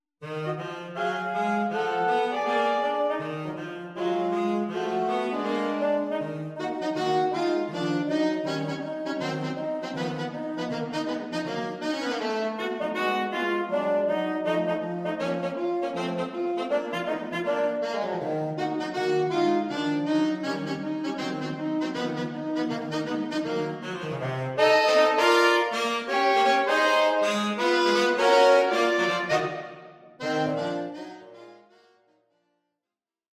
Unusual performance, using a saxophone trio of
three up-beat arrangements
Soprano Tenor Baritone